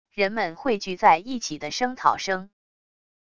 人们汇聚在一起的声讨声wav音频